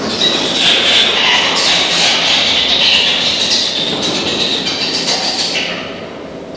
Fruit Bat
fruitbat_sound.au